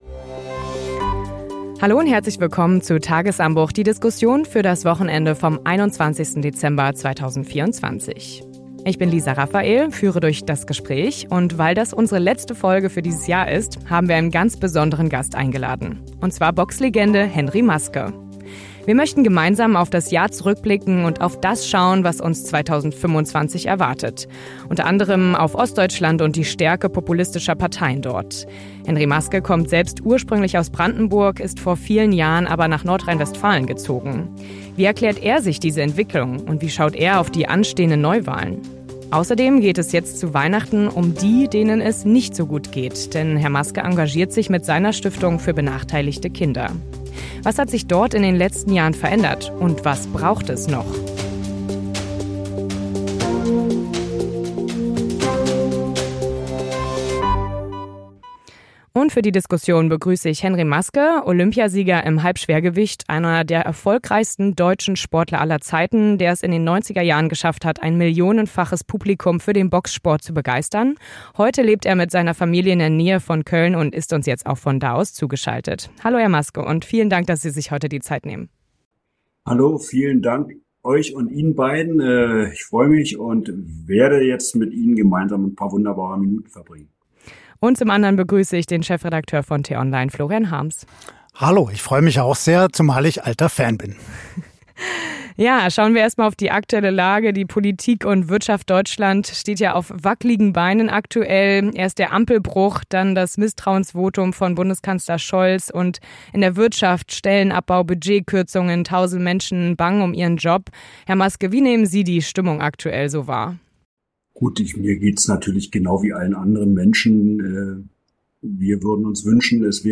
Zum Jahresabschluss haben wir einen ganz besonderen Gast im Podcast: Boxweltmeister Henry Maske.